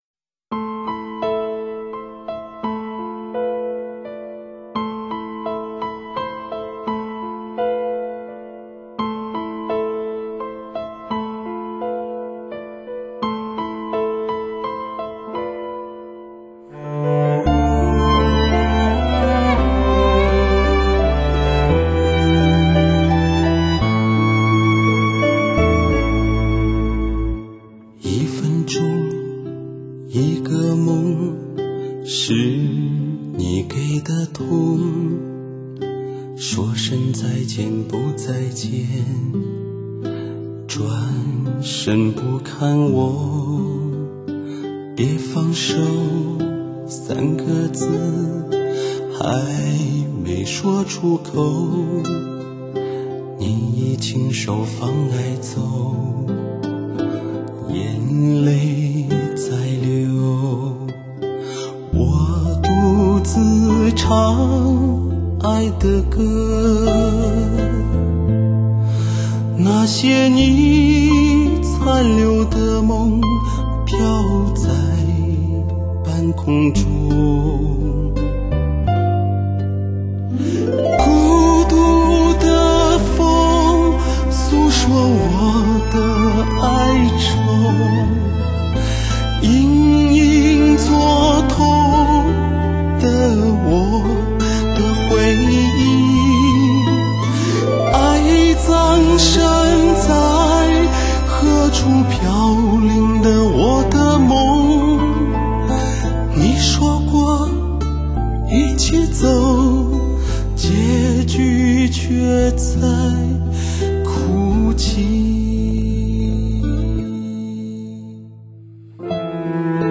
无论是精彩的编曲,还是极为严谨的录音,都透出这一诚意,
而歌者那磁性感人的美声,更让人缠绵转侧,乐而忘返,
配器也绝对上乘,那高、低频的空间感,音场与像真度表现完美无瑕,